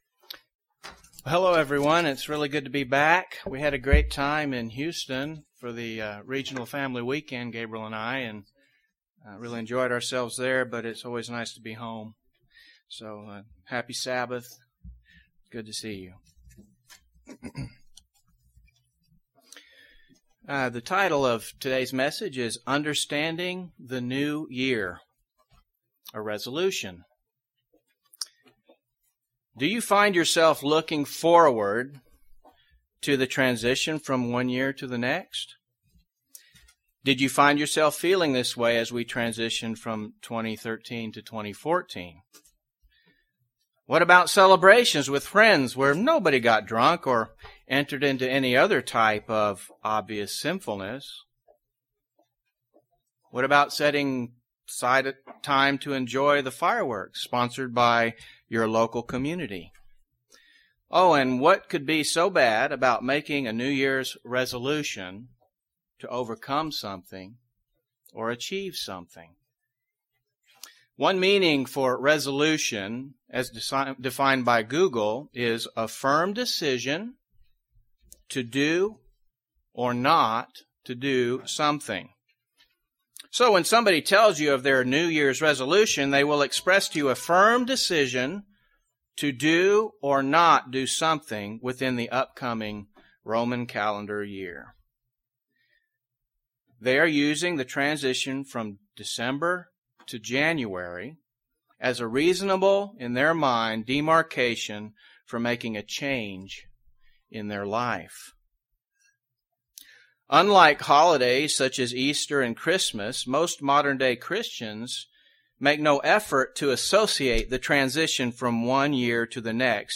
UCG Sermon New Year pagan festival new years resolution Notes PRESENTER'S NOTES Do you find yourself looking forward to the transition from one year to the next?